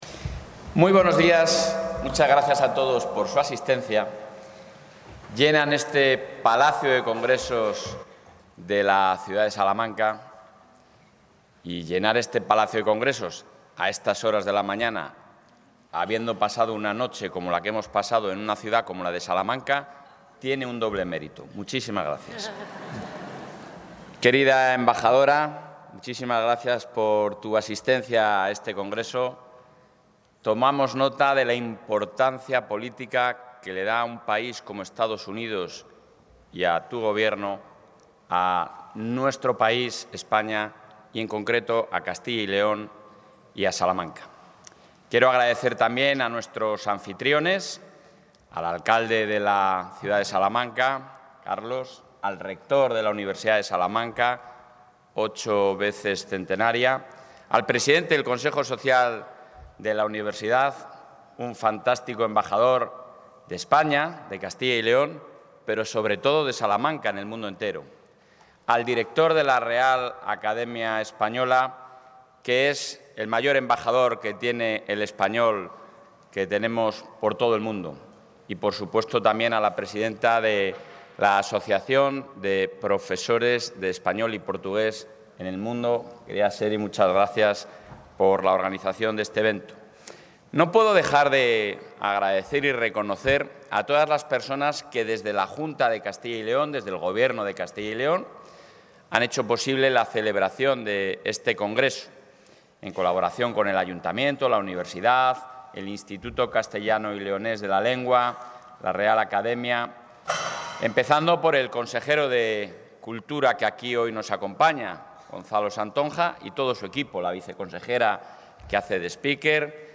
El presidente de la Junta, Alfonso Fernández Mañueco, ha inaugurado el VII Congreso Internacional del Español en Castilla y...
Intervención del presidente de la Junta.